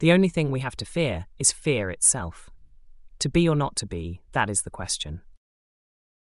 ✅ The AI Audio generator uses a
natural female neutral voice. Listen to the sample to see how it sounds.
AI Audio sample to be or not to be female fable with friendly vibe.mp3